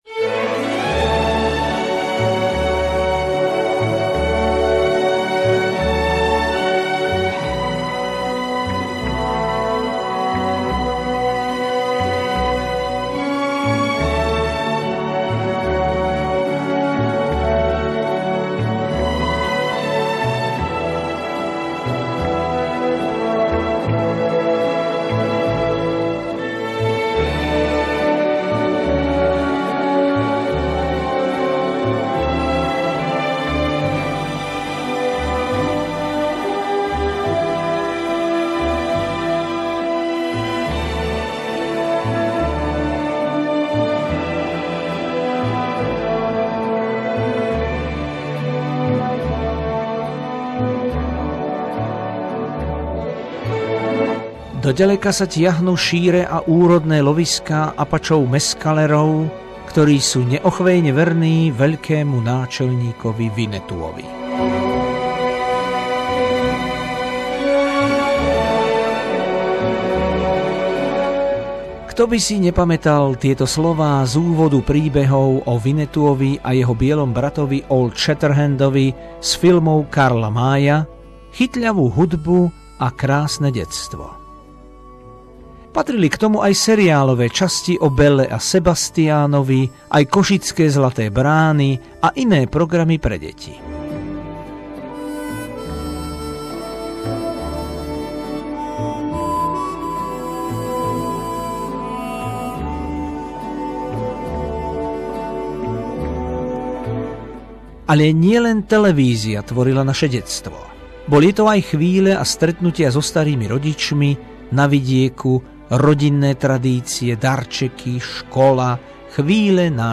Spomienky na detstvo ku dňu detí 1. júna s hudbou našej mladosti